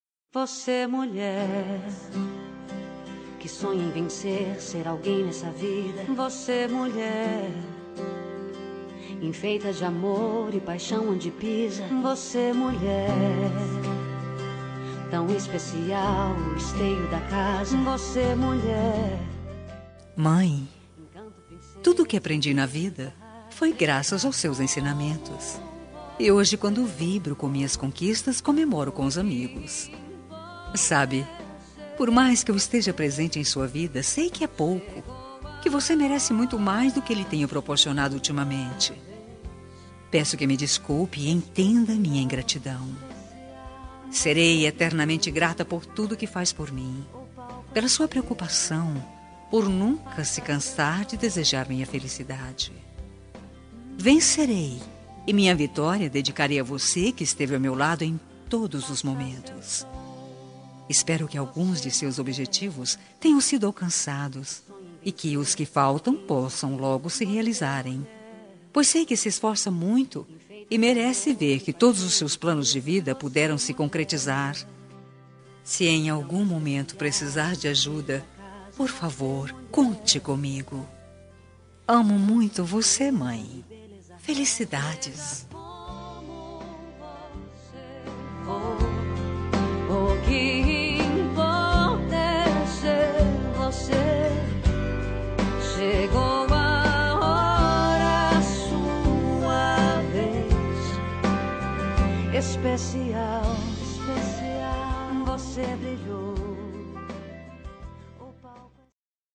Homenagem para Mãe – Voz Feminina – Cód: 8142